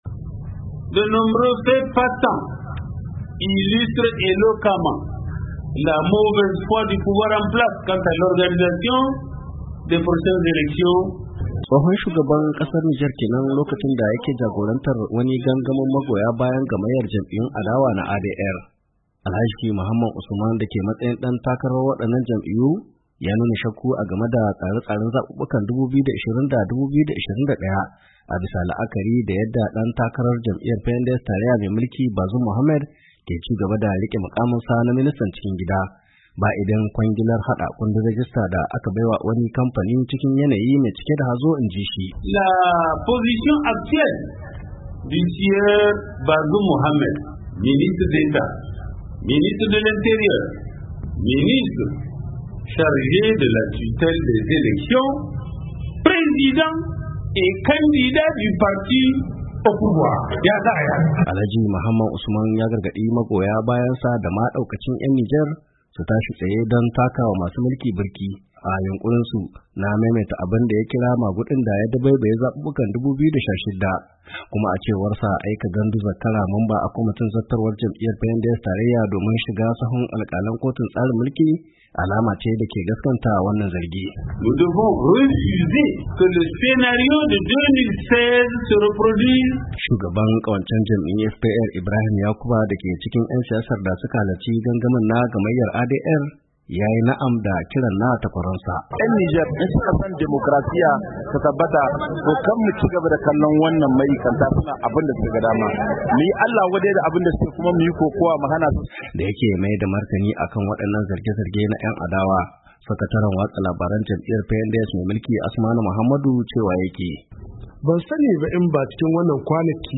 Taron gangamin gamqyyar jam'iyun ADR na tsohon shugaban kasar Nijer Mahaman Usman